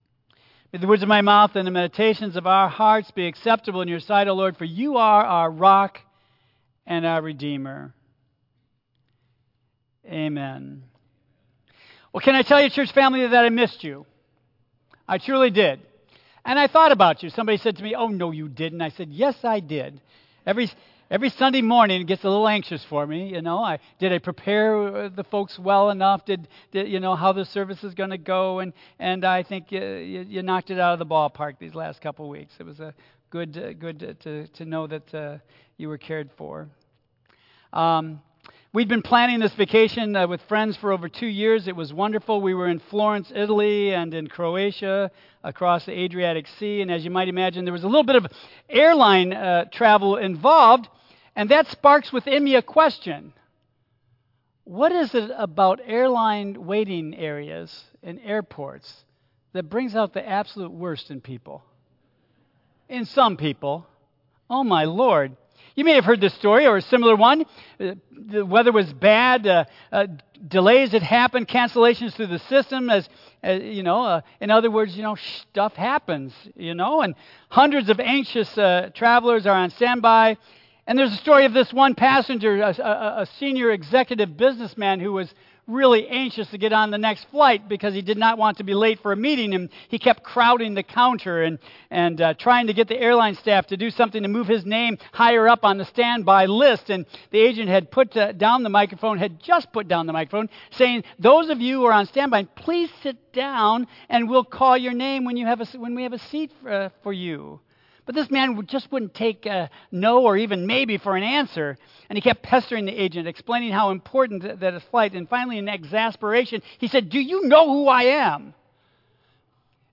Tagged with Michigan , Sermon , Waterford Central United Methodist Church , Worship